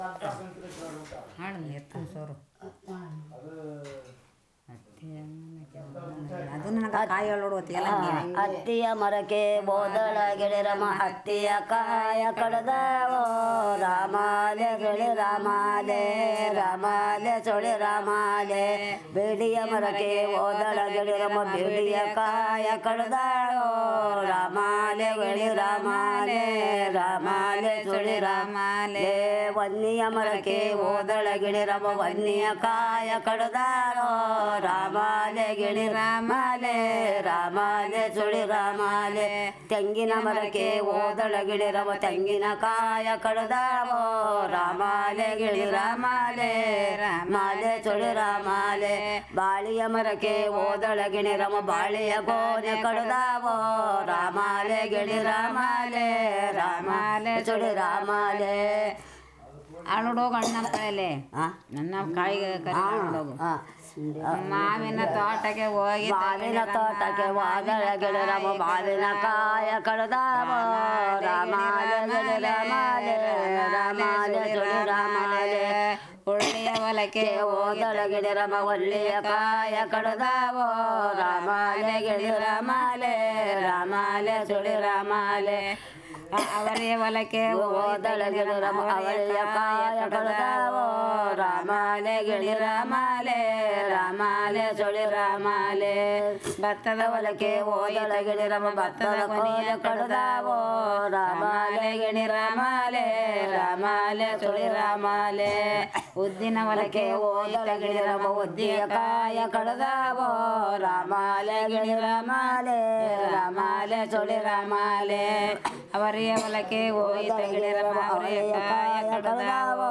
Performance of folk song 'Ramalee Gili Ramlee'